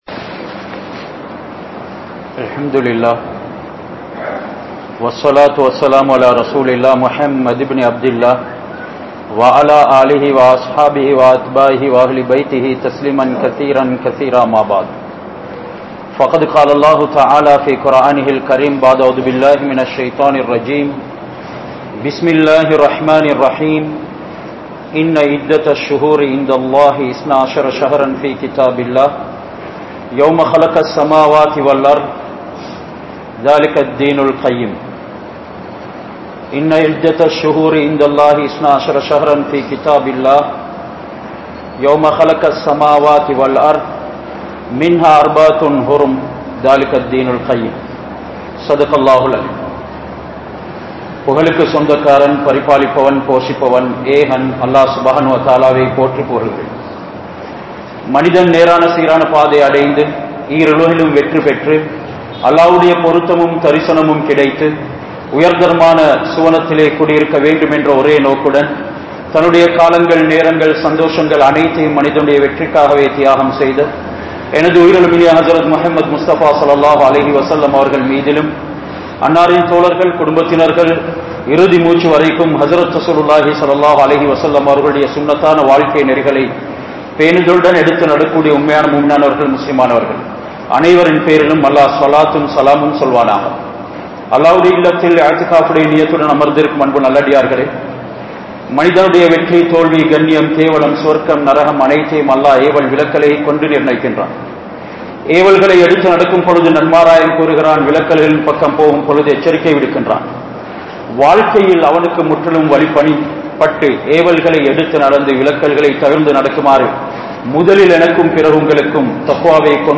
Muharram Maathathin Sirappuhal (முஹர்ரம் மாத்தத்தின் சிறப்புகள்) | Audio Bayans | All Ceylon Muslim Youth Community | Addalaichenai